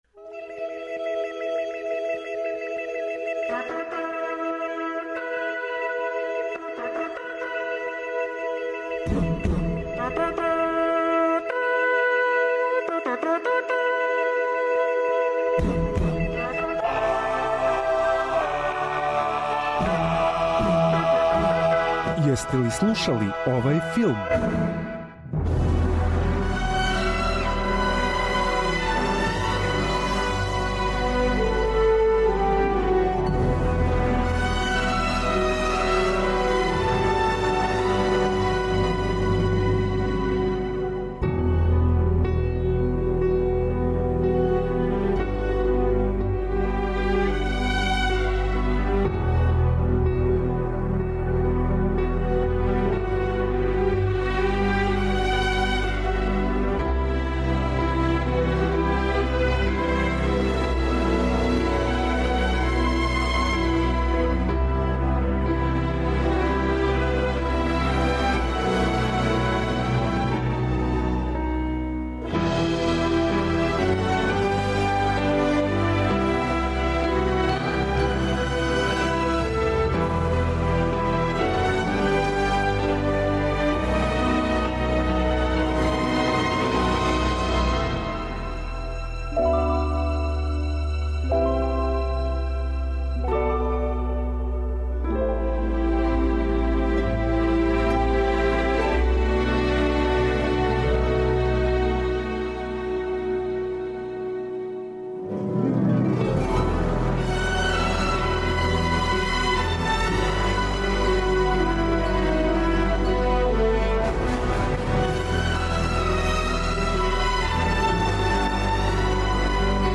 Autor: Београд 202 Филмска музика и филмске вести.